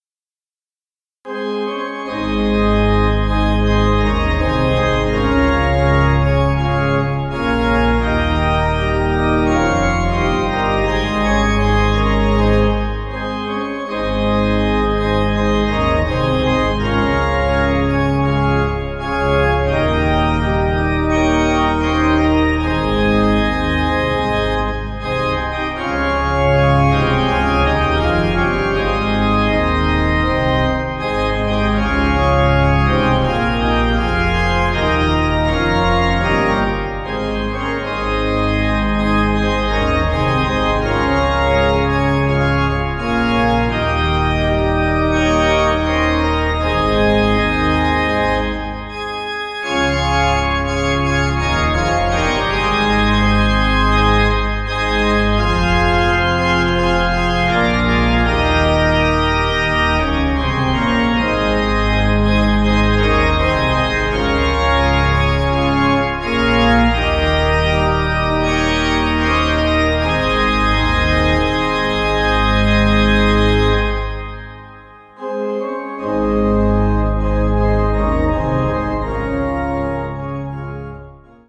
(CM)   3/Ab